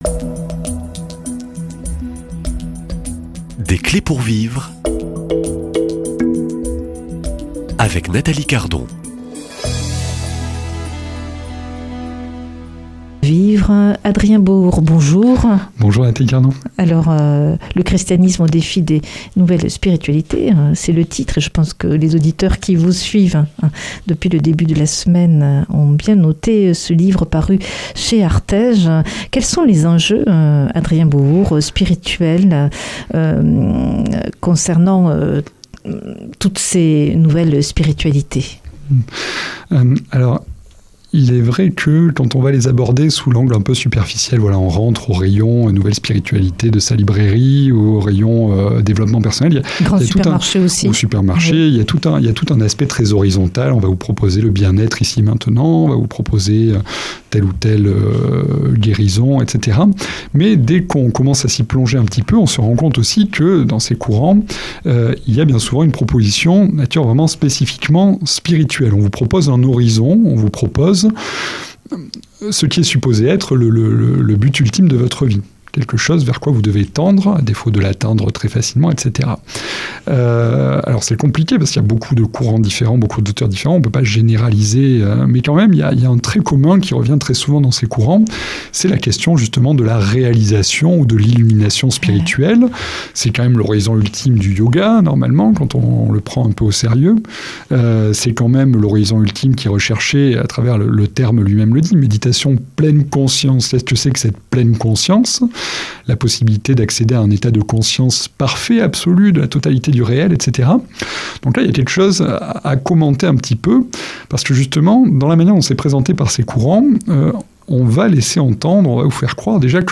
Accueil \ Emissions \ Foi \ Témoignages \ Des clés pour vivre \ Quels sont les enjeux de ces nouvelles spiritualités ?